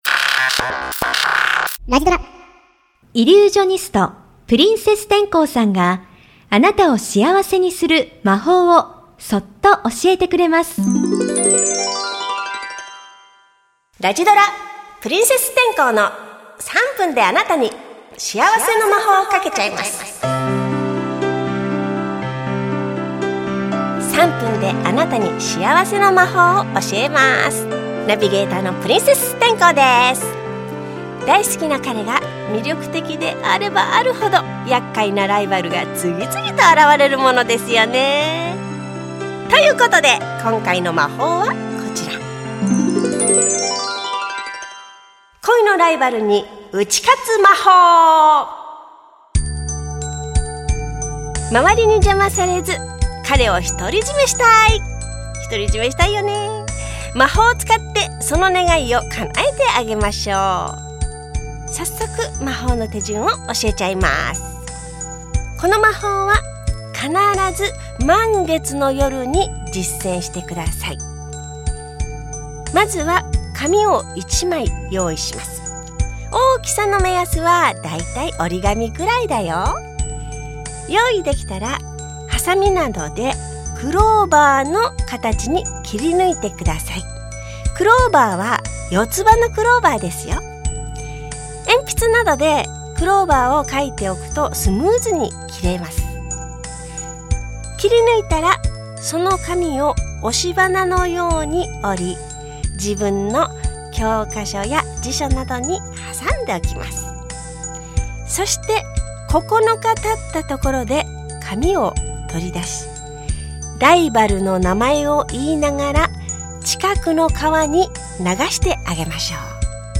[オーディオブック] ラジドラ プリンセス天功の3分であなたに幸せの魔法かけちゃいます